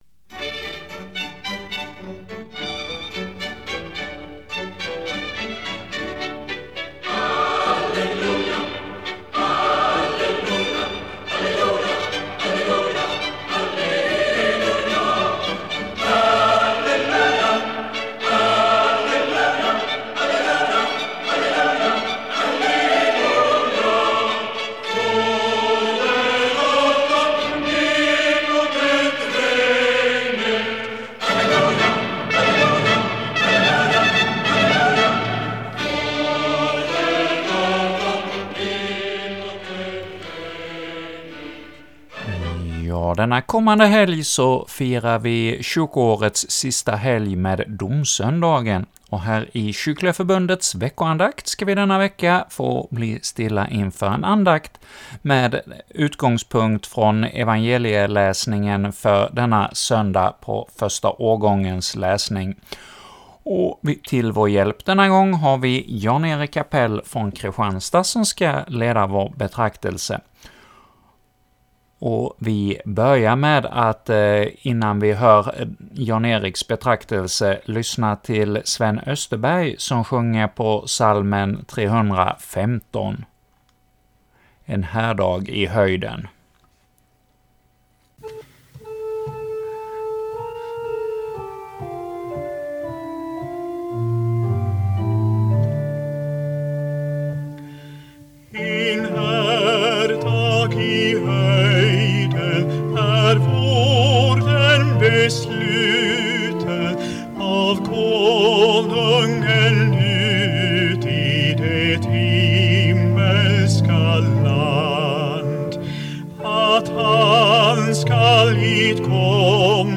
leder andakt inför Domsöndagen